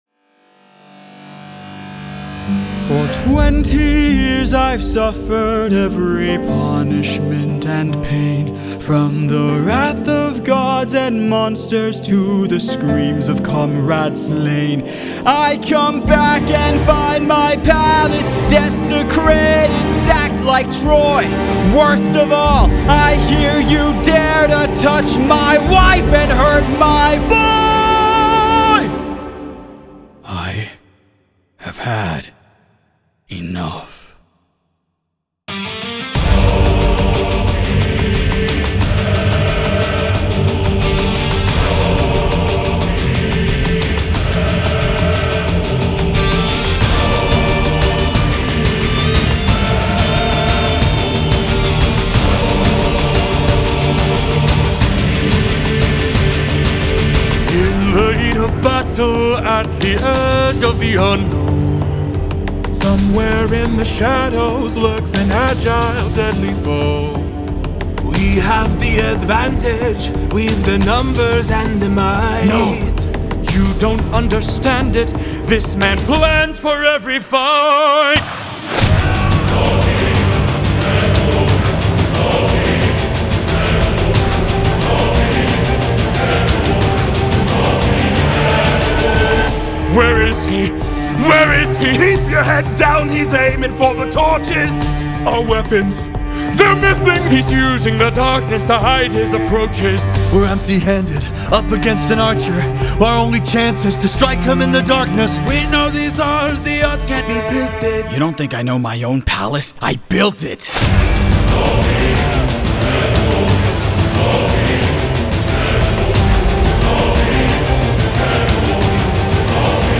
Трек размещён в разделе Зарубежная музыка / Поп.